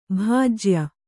♪ bhājya